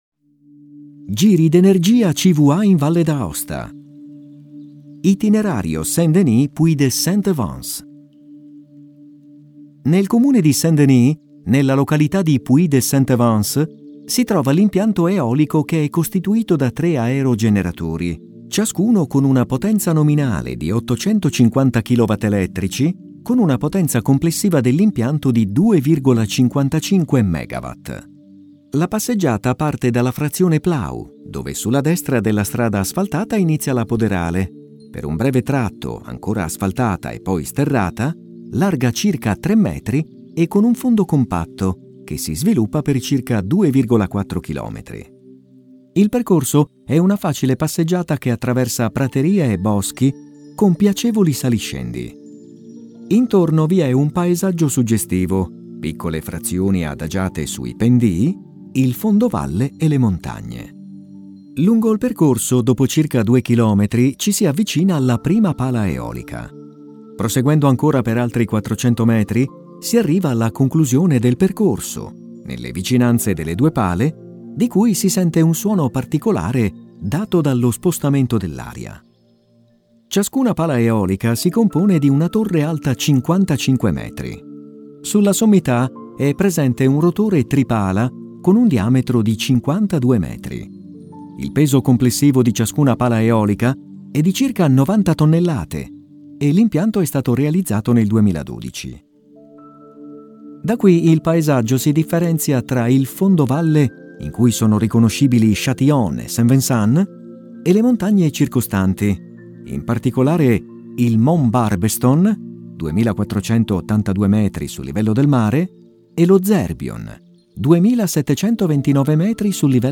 Audioguida Video LIS